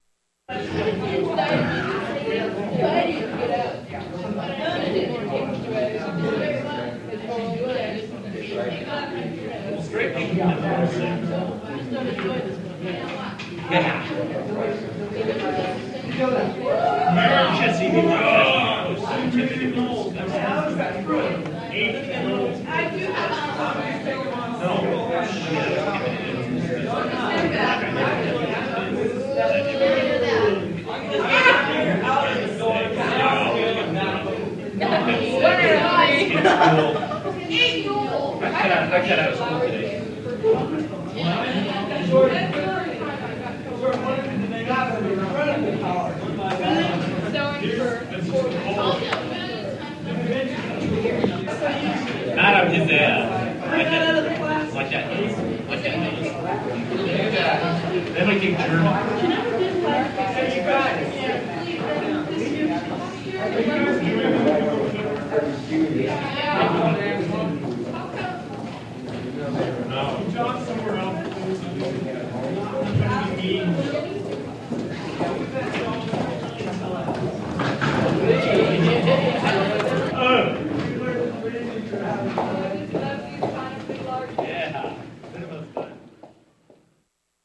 Student Walla
Classroom School Walla Students Interior